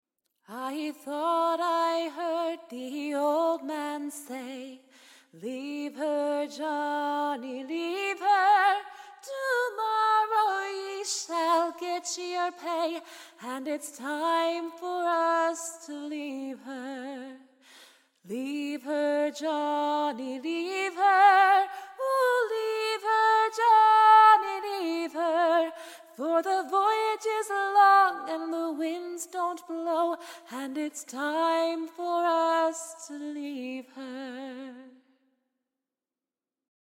shanty